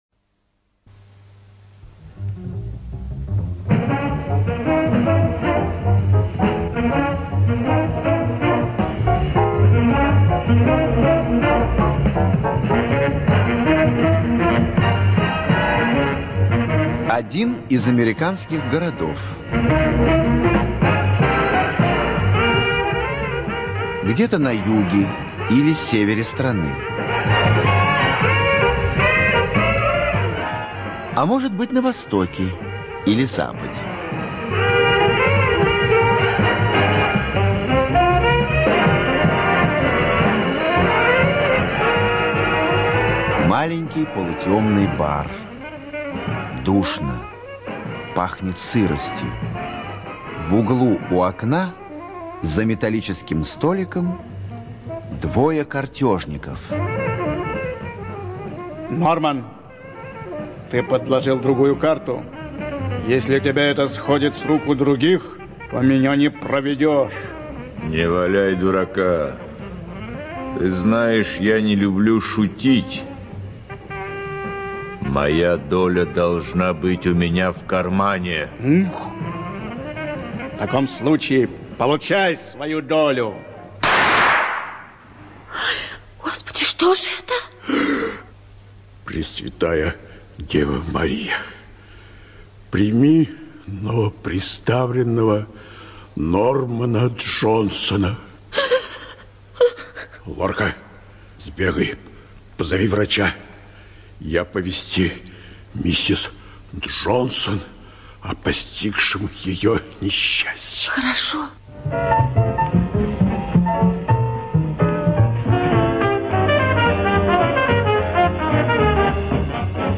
ЧитаютПлятт Р., Понсова Е.
ЖанрРадиоспектакли